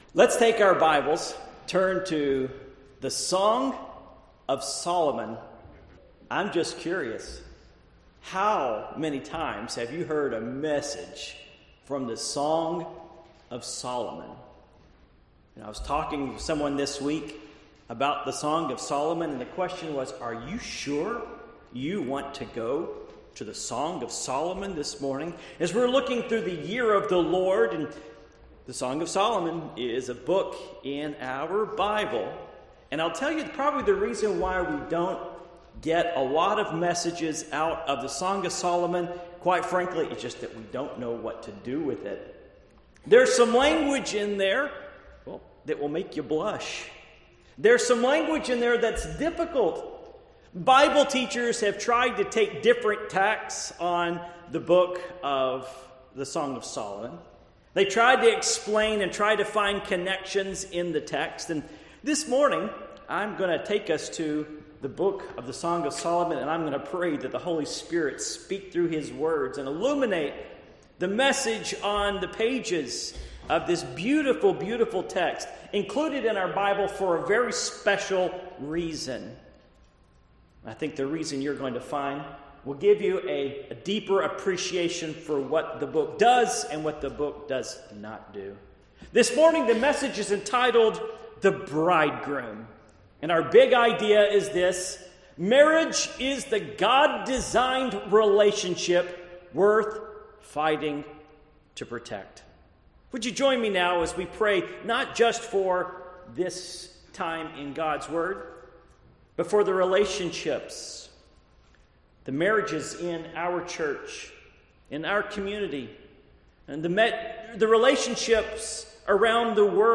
Passage: Song of Solomon 2:1-5 Service Type: Morning Worship